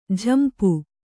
♪ jhampu